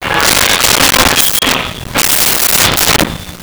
Door Screen Open Slam
Door Screen Open Slam.wav